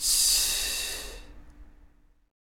｢C｣がハッキリと聴こえると思います。